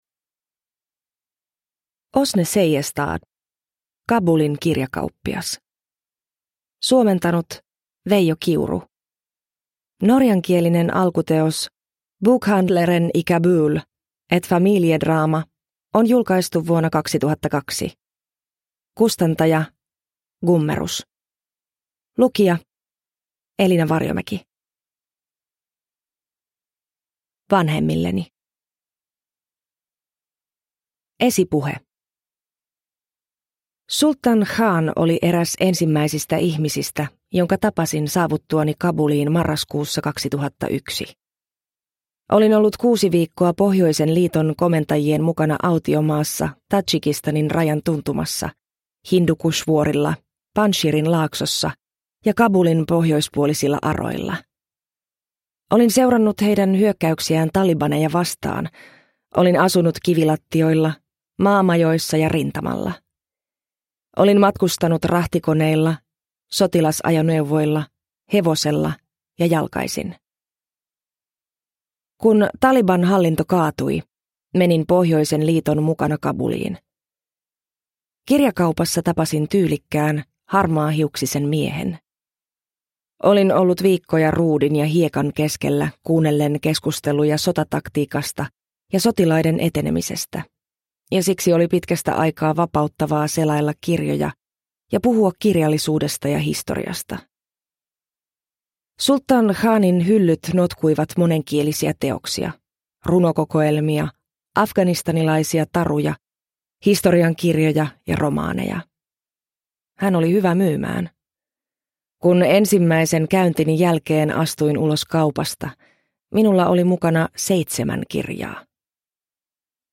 Kabulin kirjakauppias – Ljudbok – Laddas ner